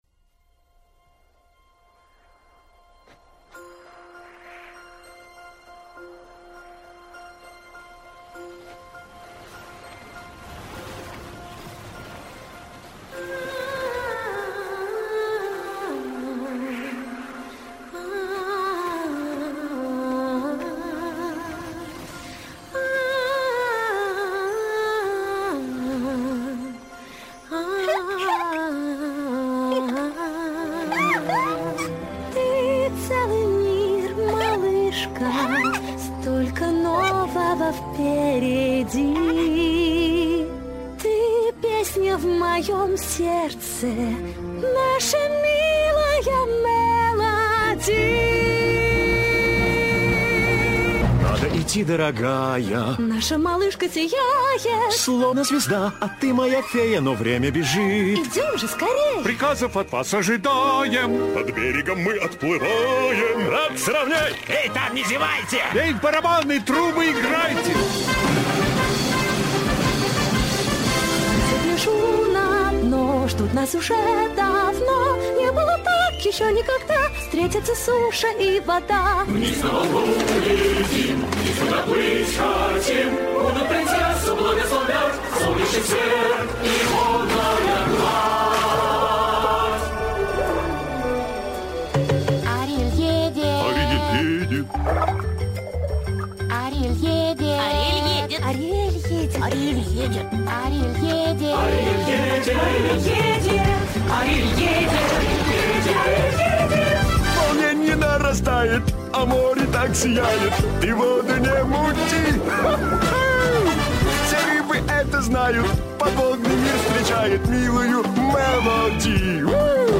• Качество: Хорошее
• Жанр: Детские песни
🎶 Детские песни / Песни из мультфильмов